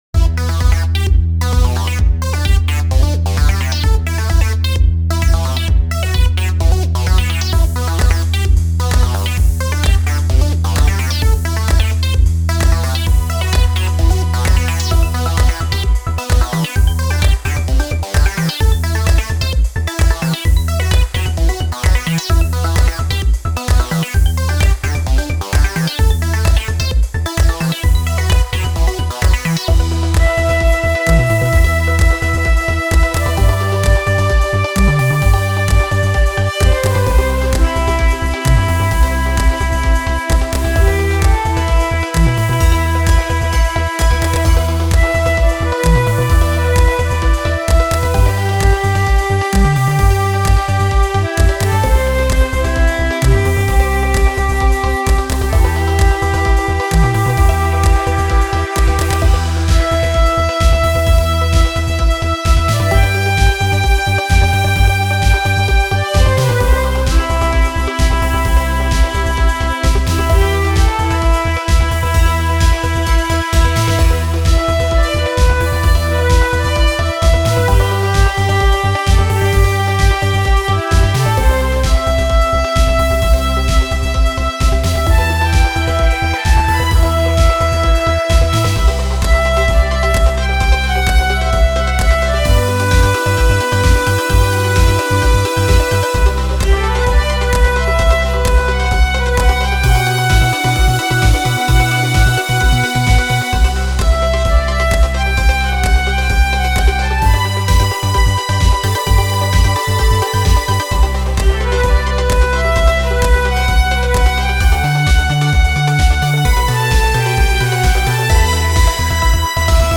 フリーBGM フィールド・ダンジョン ダンジョン
フェードアウト版のmp3を、こちらのページにて無料で配布しています。